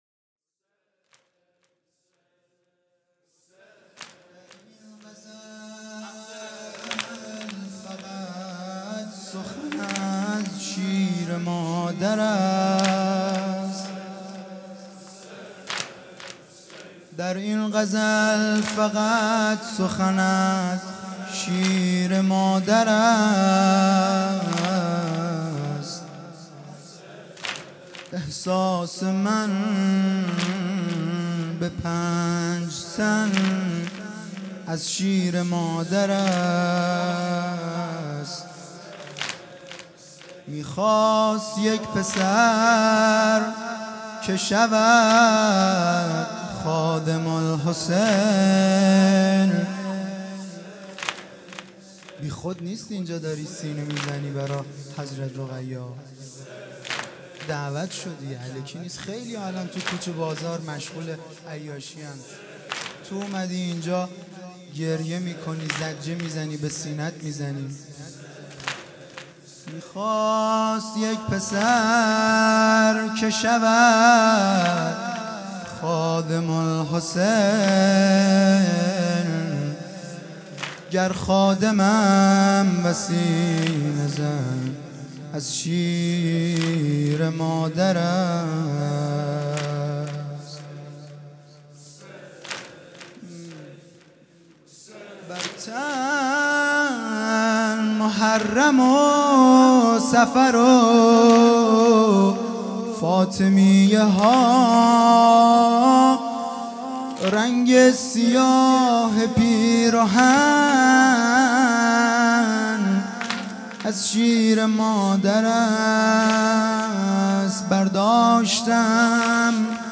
شعر خوانی
در این غزل فقط سخن از|شب سوم محرم